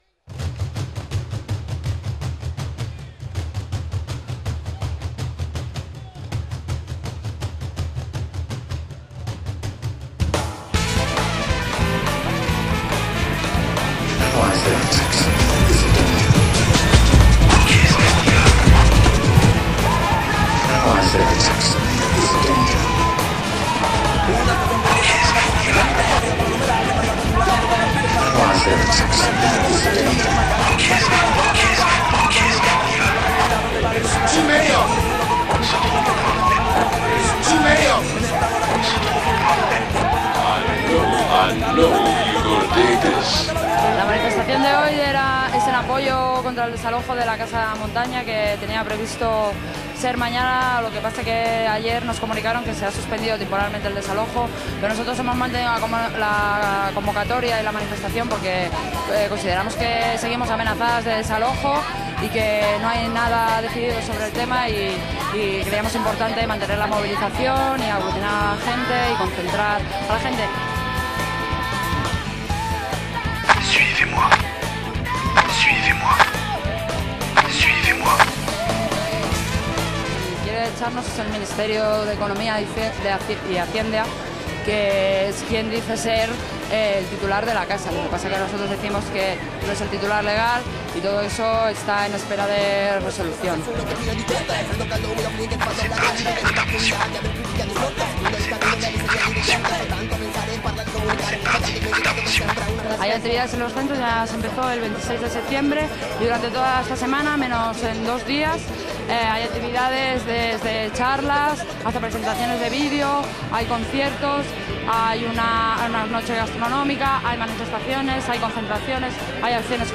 Reportatge sobre el desatllojament dels esquàters que vivien a la Kasa de la Muntanya, al barri de Gràcia
Informatiu